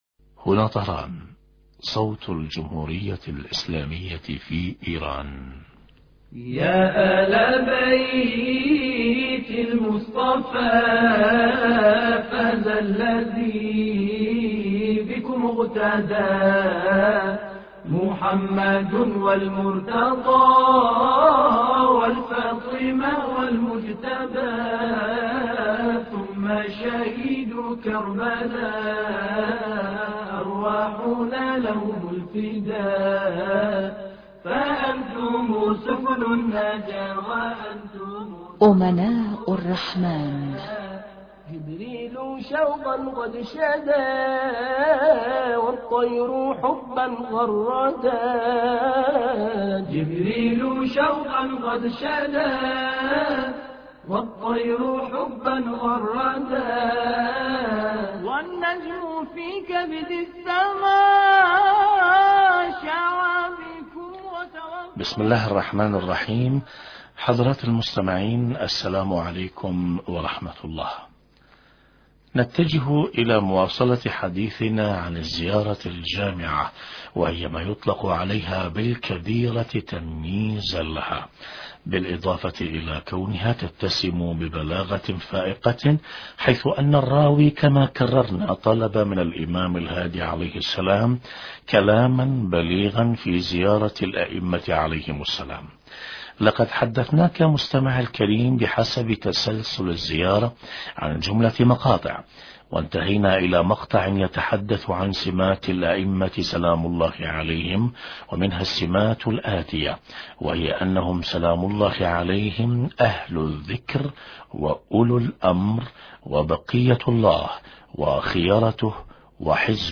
بيان معنى صفات أهل الذكر وأولي الامر من مقامات أهل البيت(ع) خبير البرنامج وحوار عن دور الائمة(ع) في حفظ الدين شرح فقرة: أهل الذكر وأولي الامر